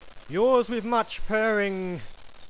Purr1
purr1.WAV